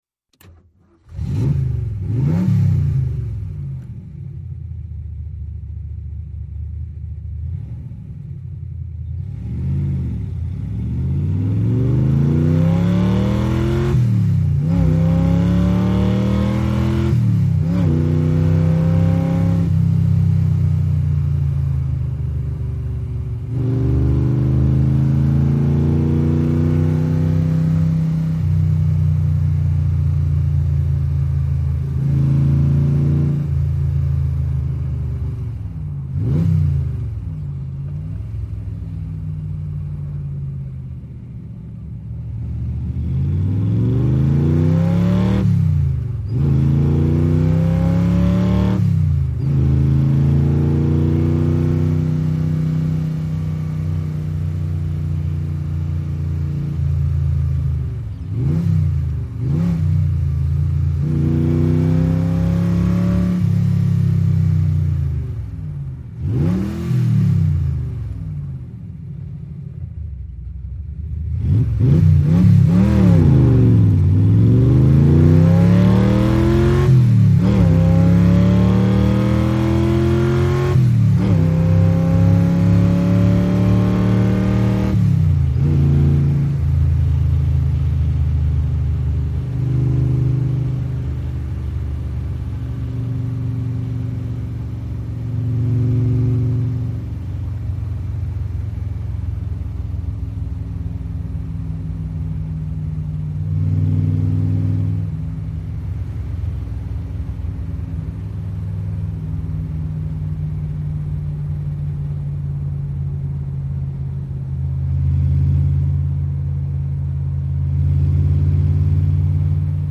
Cobra; Interior; Start And Idle, Fast Acceleration Through Gears, Squeak With Gear Shift. Driving In Traffic, Various Speeds. Some Interior Instrument Squeaks, Hiss Of Motor Ventilation. Off With K